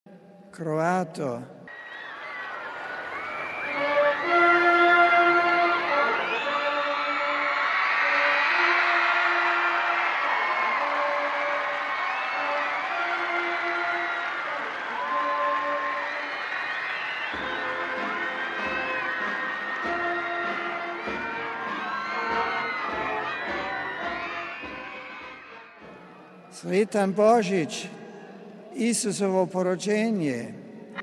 Zbog toga je pozvao pojedince i narode da povjerenjem, poniznošću i bez straha pohrle k betlehemskom djetešcu, jer donosi ljubav Nebeskog Oca i pokazuje put mira – ovim je riječima završio svoj nagovor Benedikt XVI., a zatim je čestitao Božić na različitim jezicima među kojima i na hrvatskom: RealAudio